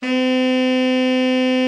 TENOR 17.wav